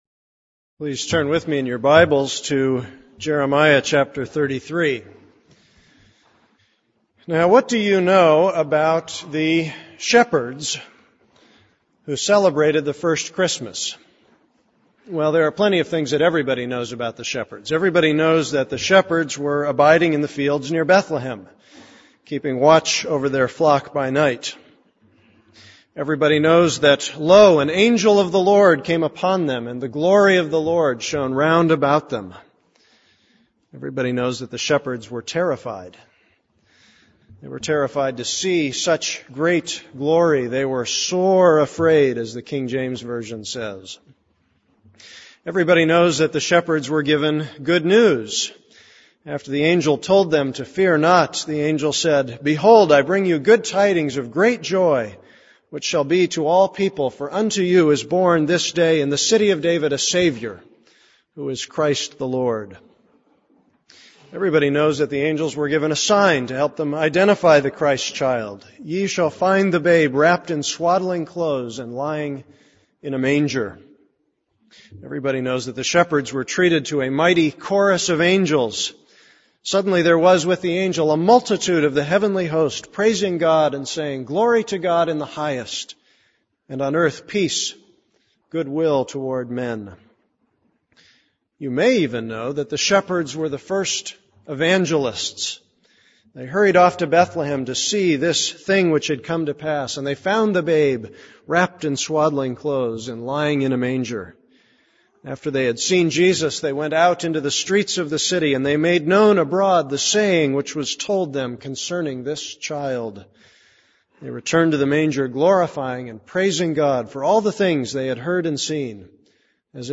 This is a sermon on Jeremiah 33:10-15.